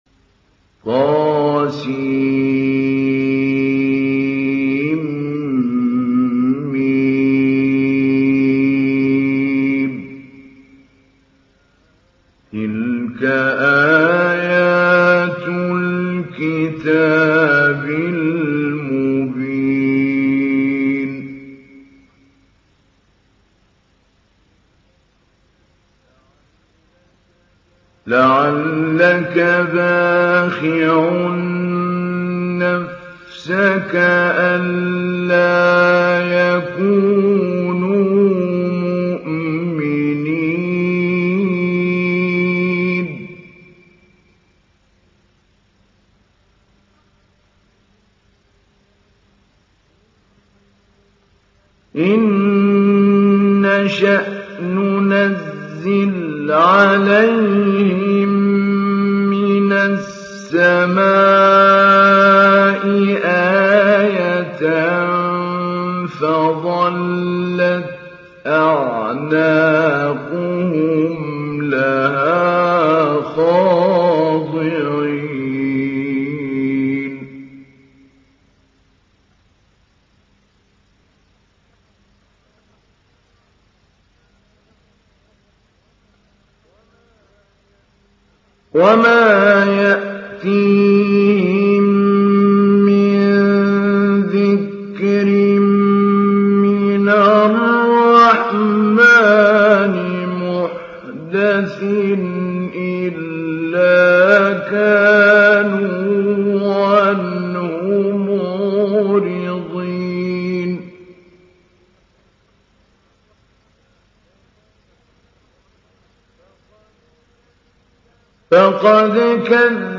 Download Surah Ash Shuara Mahmoud Ali Albanna Mujawwad